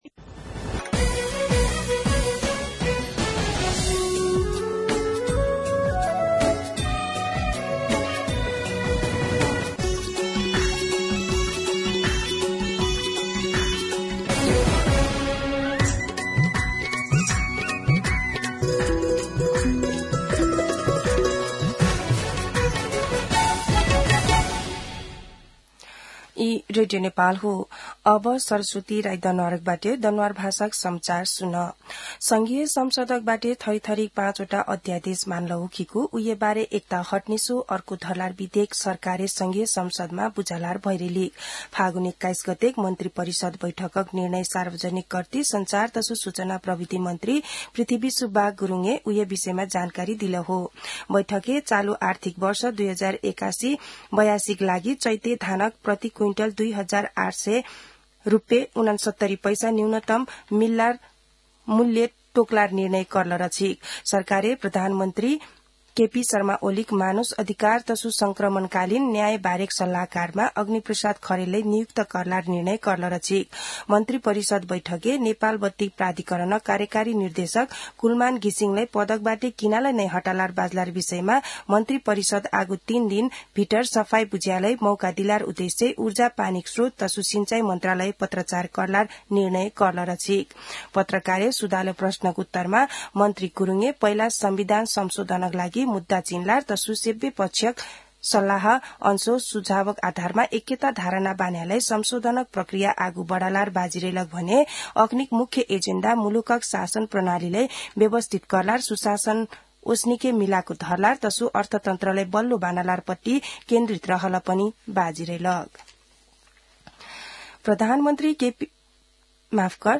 दनुवार भाषामा समाचार : २४ फागुन , २०८१
Danuwar-News-23.mp3